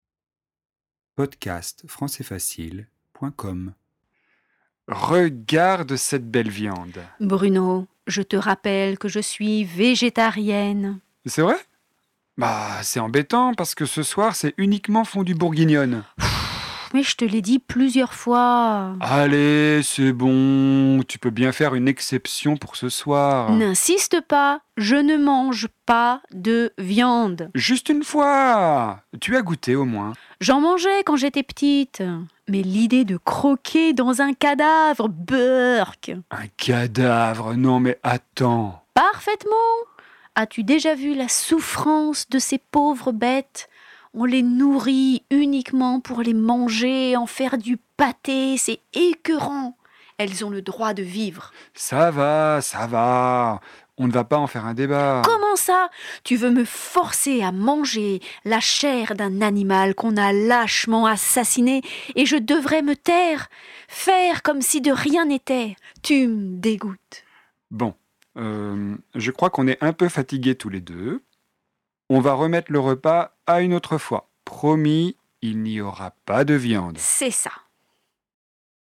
🔷  DIALOGUE :